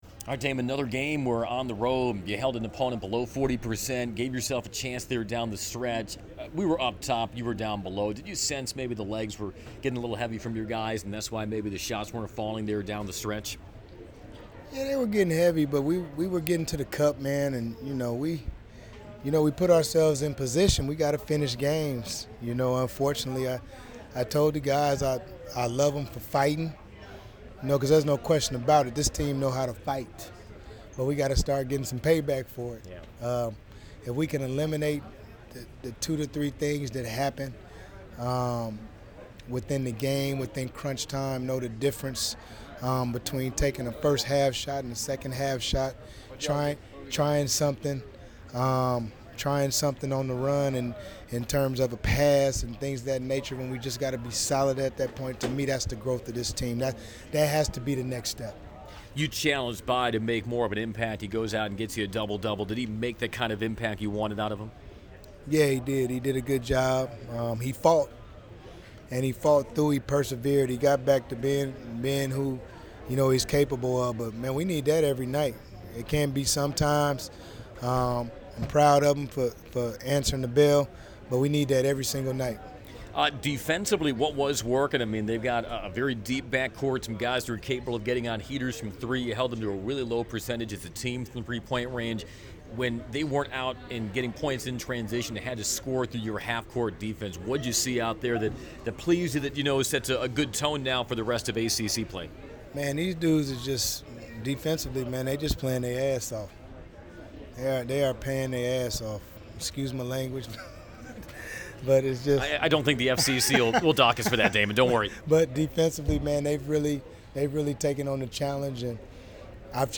STOUDAMIRE POST-GAME AUDIO
Damon-Postgame-vs.-UNC.m4a